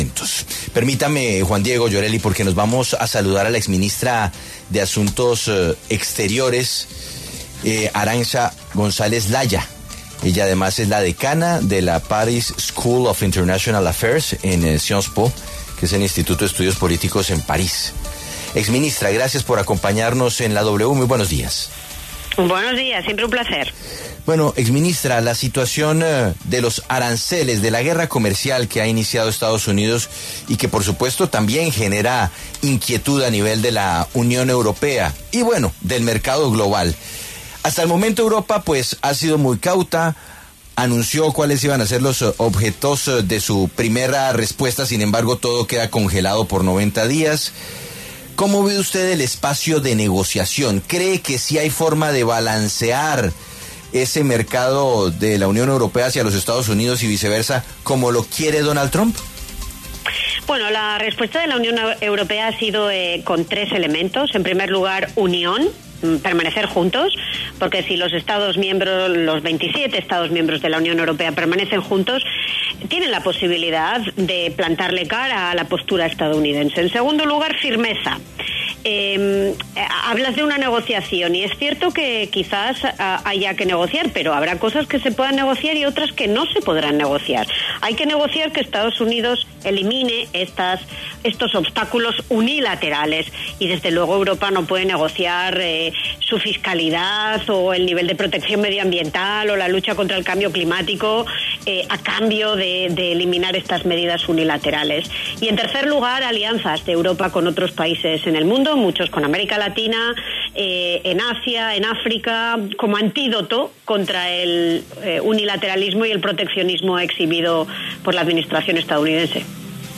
Arantxa González, exministra de Asuntos Exteriores de la Unión Europea, habló en La W sobre la guerra comercial con Estados Unidos por los aranceles anunciados por Donald Trump.